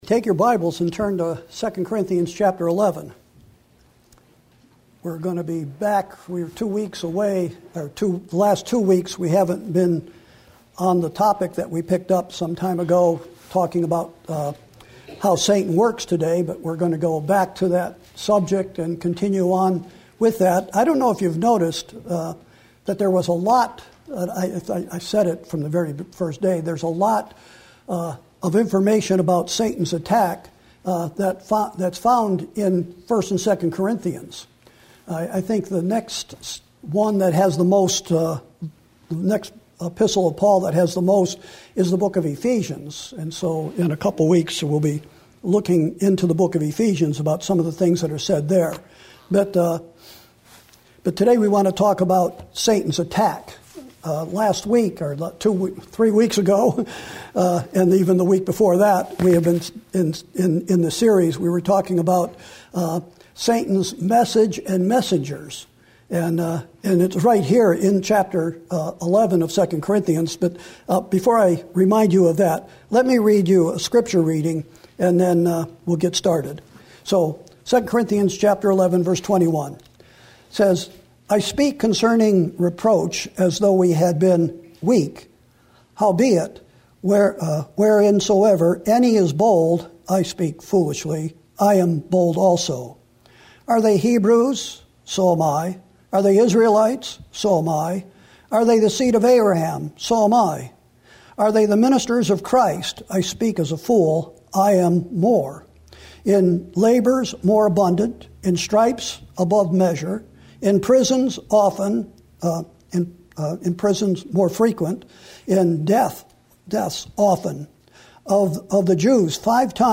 Sermons & Single Studies / Sunday 11am